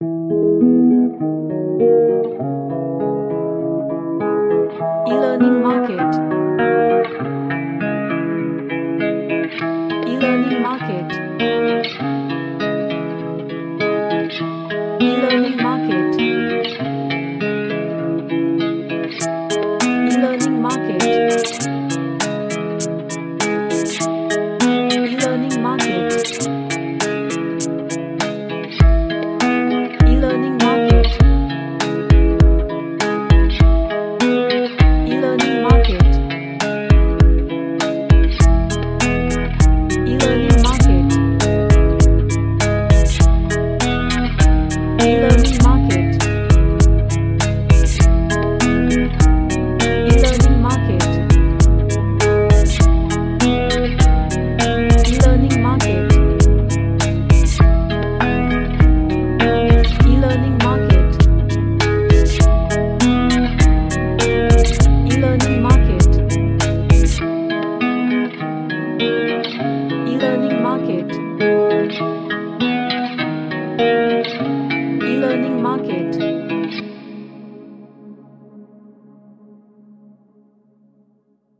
A chill pop track with arps
Chill Out